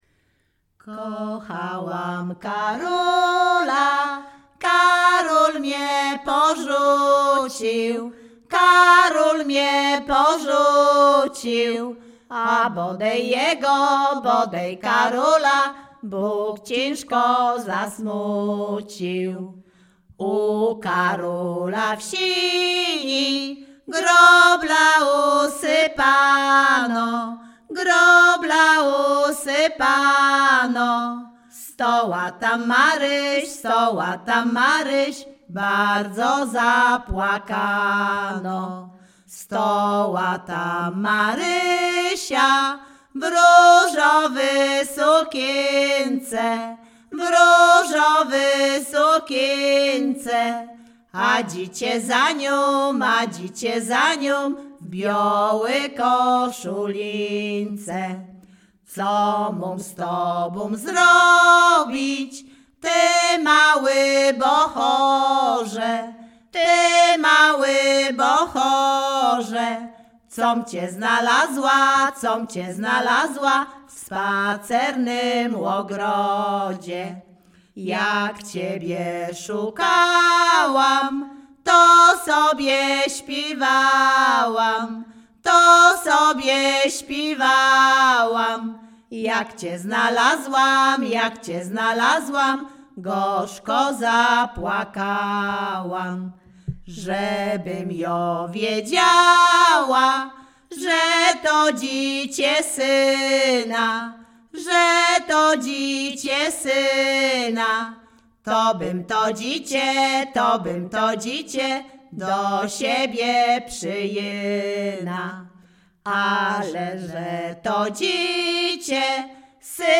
Śpiewaczki z Chojnego
województwo łódzkie, powiat sieradzki, gmina Sieradz, wieś Chojne
liryczne miłosne żartobliwe